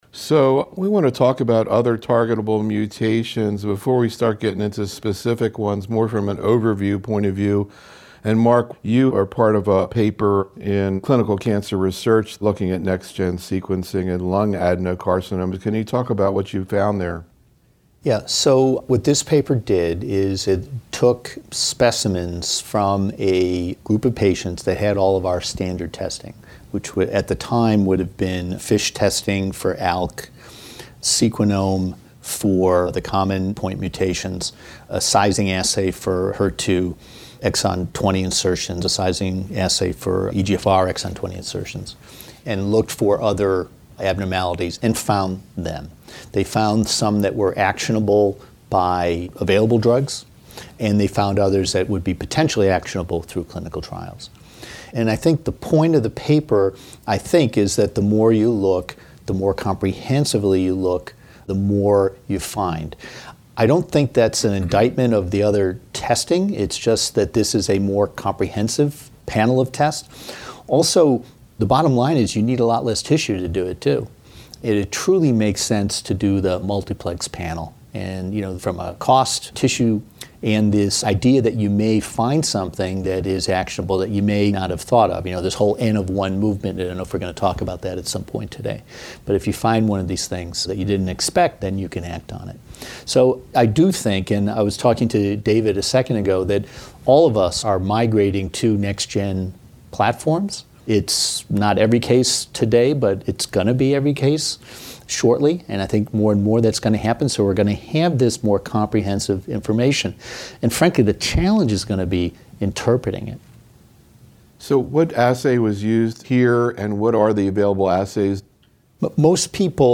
In these audio proceedings from a closed Think Tank meeting held in January 2015, the invited faculty discuss and debate some of the key clinical management issues in lung cancer as well as promising research strategies in this area. The roundtable discussion focused on key presentations and papers and actual cases managed in the practices of the faculty in which these data sets factored into their decision-making.